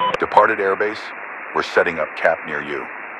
Radio-pilotNewFriendlyAircraft5.ogg